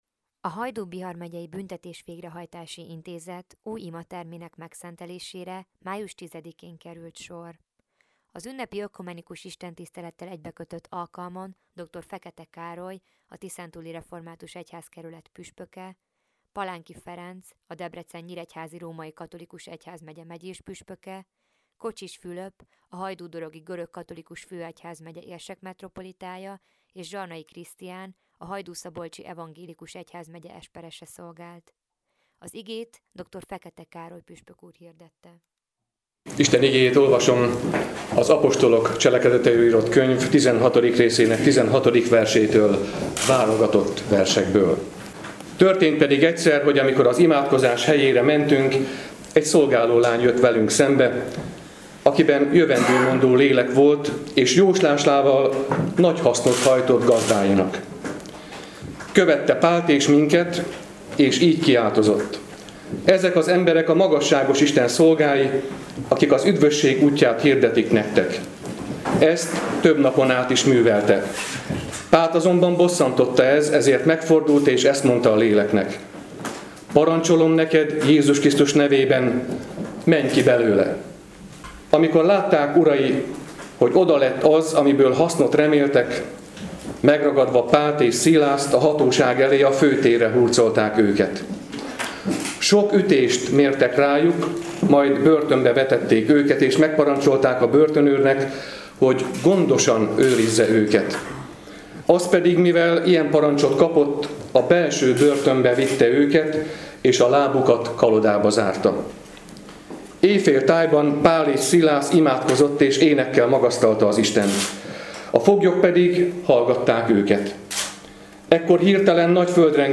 0510-imaterem-megszenteles-teljes.mp3